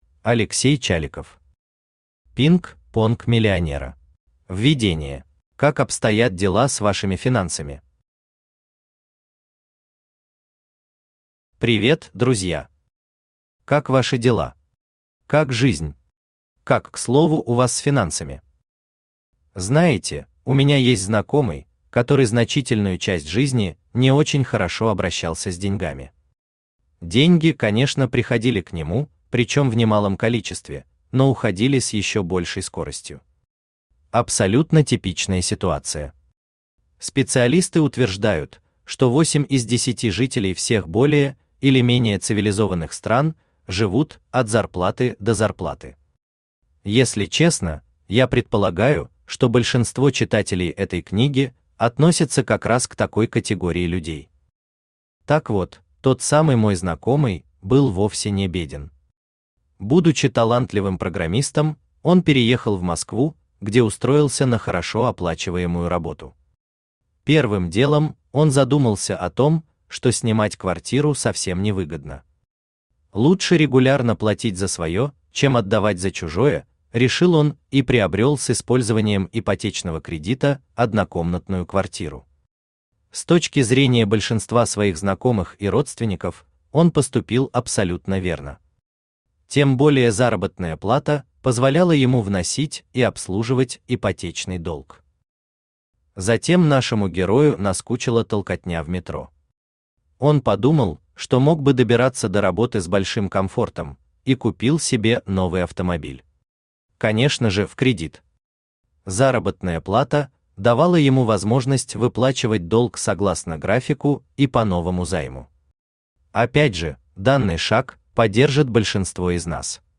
Аудиокнига ПИНК ПОНК миллионера | Библиотека аудиокниг
Aудиокнига ПИНК ПОНК миллионера Автор Алексей Чаликов Читает аудиокнигу Авточтец ЛитРес.